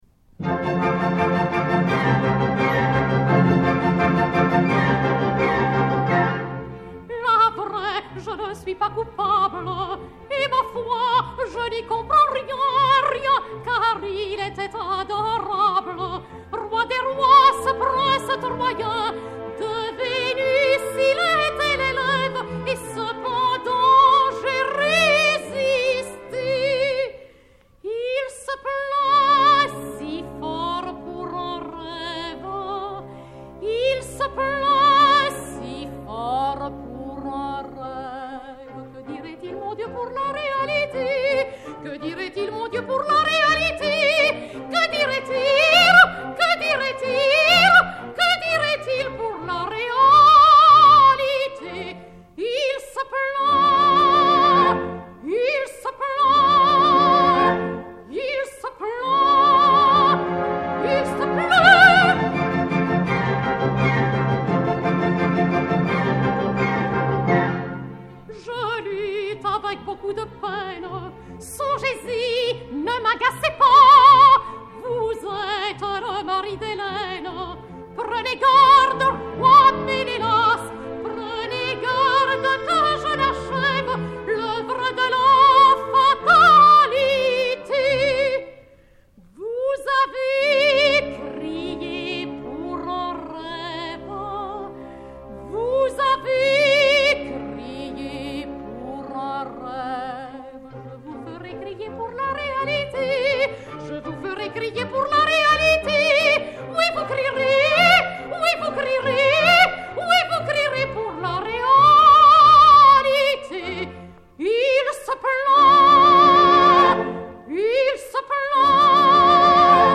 45 tours Orphée, enr. en 1964